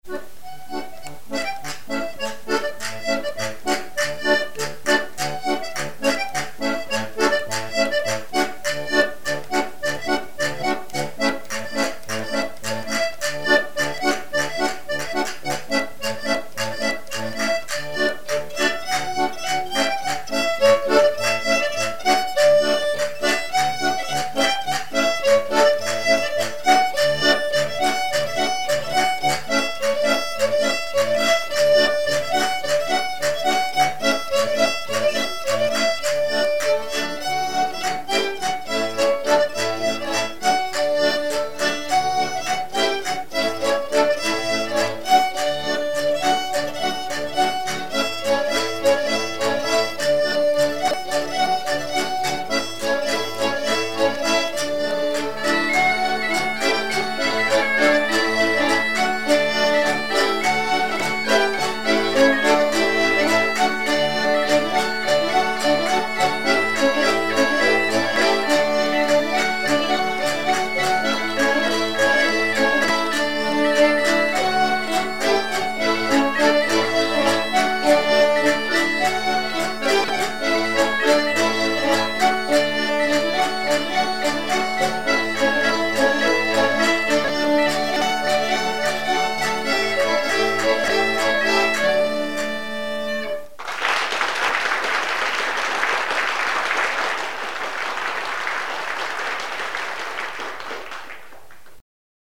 Chants brefs - A danser
Pièce musicale éditée